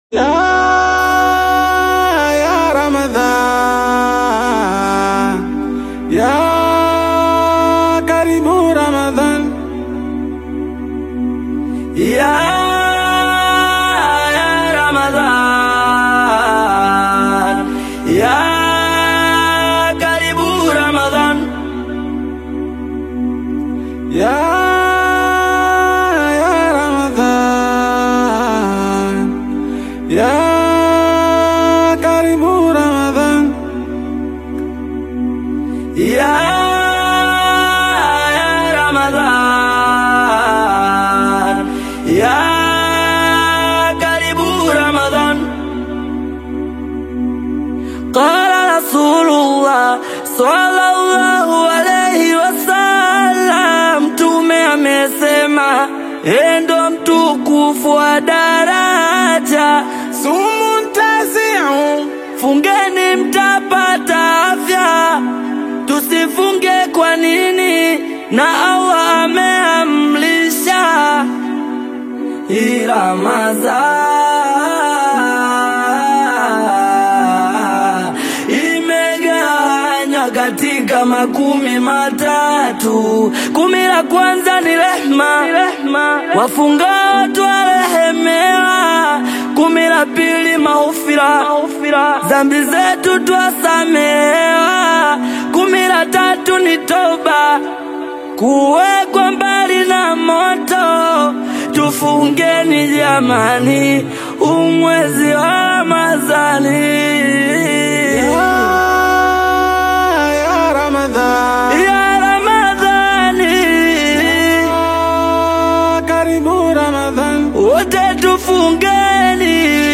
spiritually uplifting Tanzanian gospel single
heartfelt melodies
emotive delivery and gospel‑inspired sound